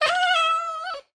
豹猫死亡时随机播这些音效
Minecraft_ocelot_death3.mp3